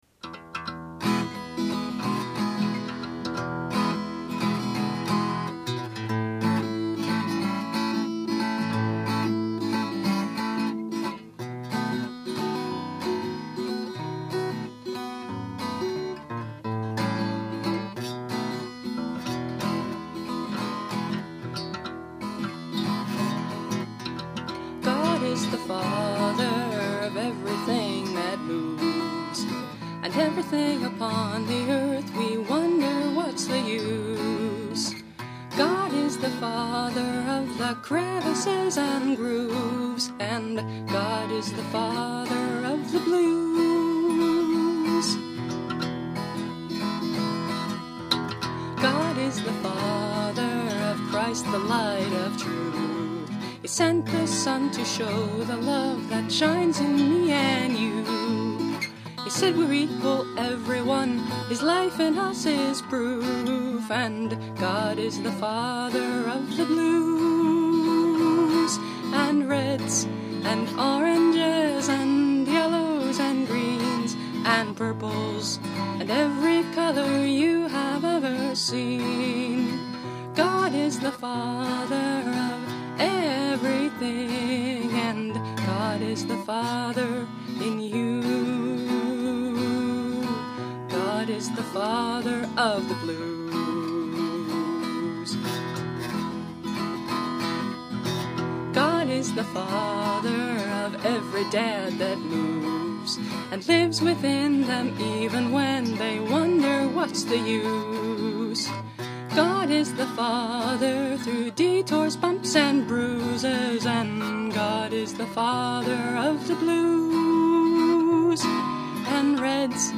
Instrument: Tempo – Seagull Excursion Folk Acoustic Guitar